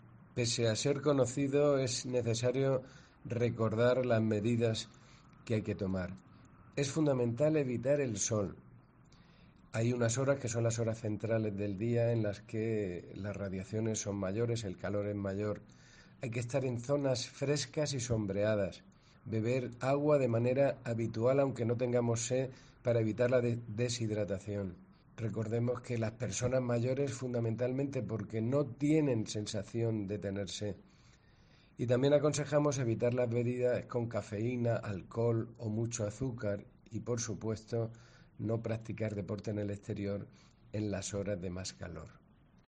José Jesús Guillén, director general de Salud Pública y Adicciones